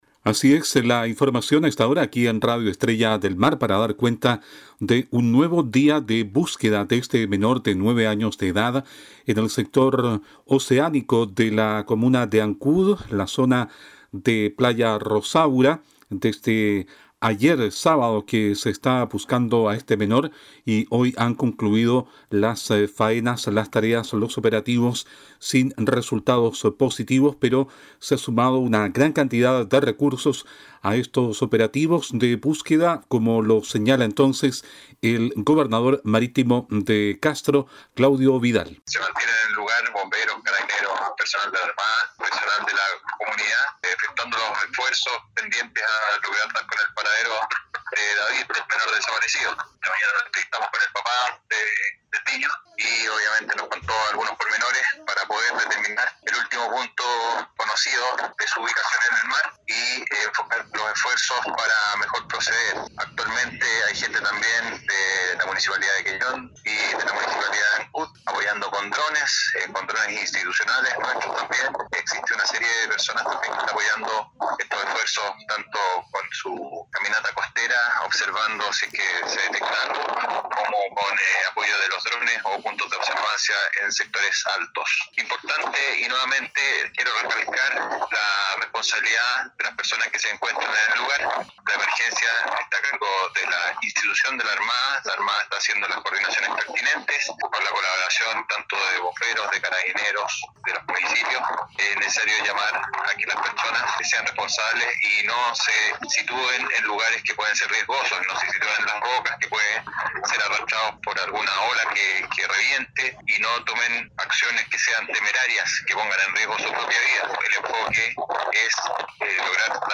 DESPACHO-GOB-MARITIMO-CASTRO-X-BUSQUEDA.mp3